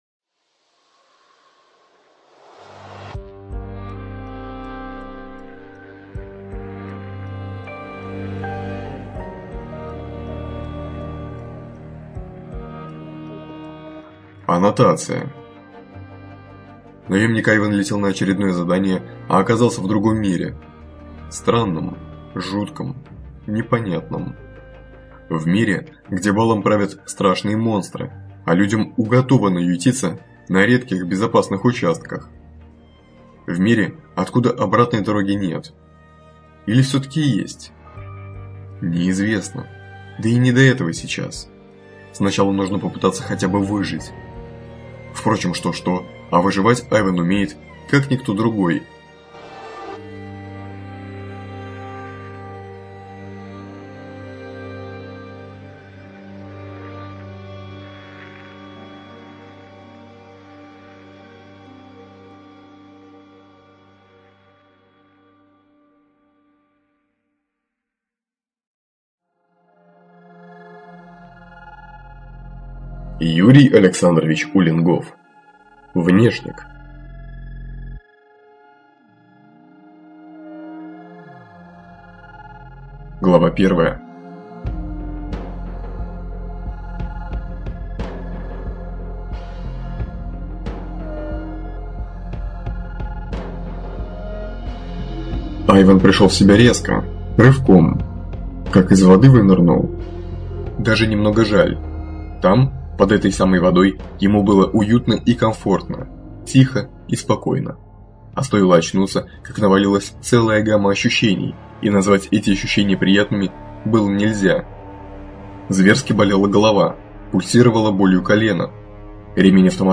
ЖанрФантастика, Боевики